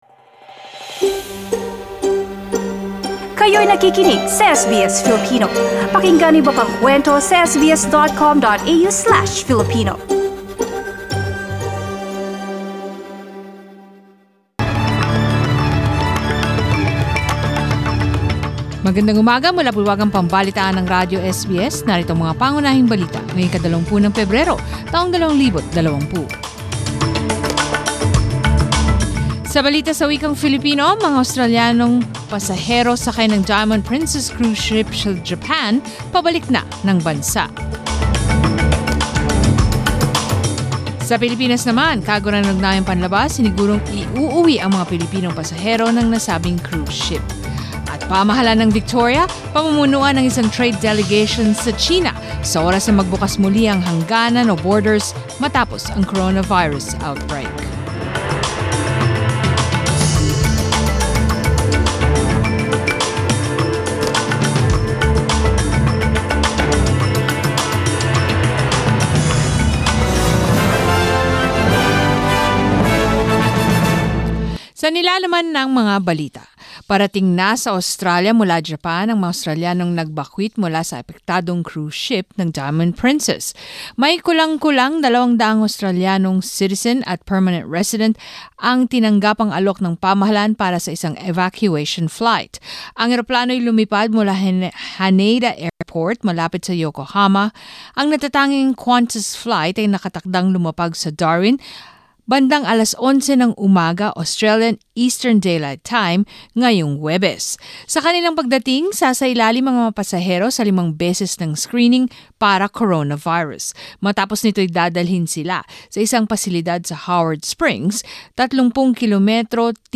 SBS News in Filipino, Thursday 20 February